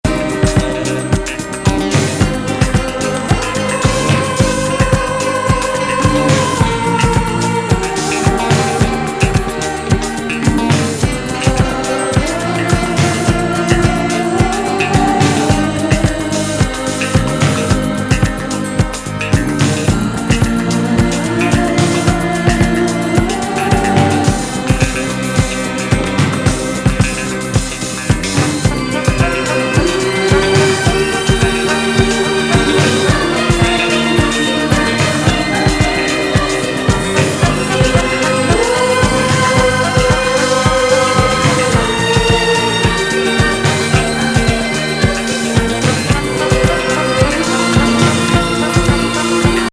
怪しげストリングス&女性コーラスの、エキセントリック・